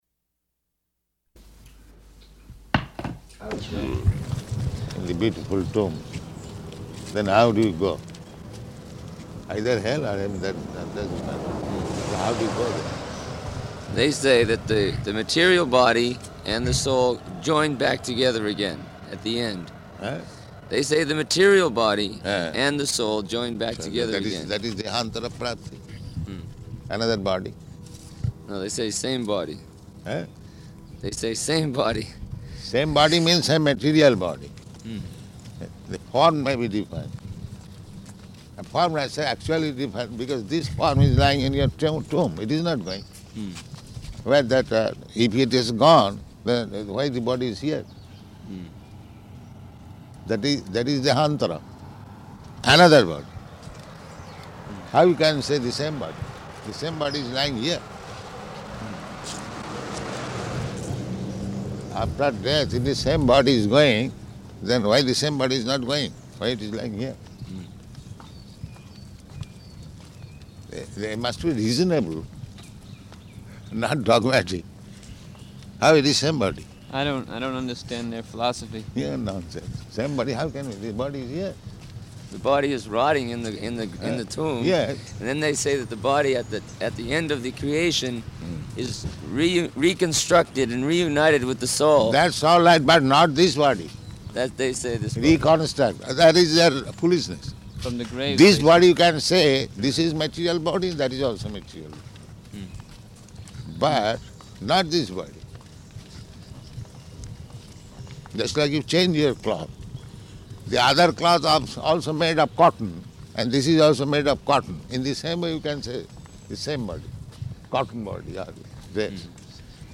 Morning Walk --:-- --:-- Type: Walk Dated: June 29th 1974 Location: Melbourne Audio file: 740629MW.MEL.mp3 Prabhupāda: ...in the beautiful tomb.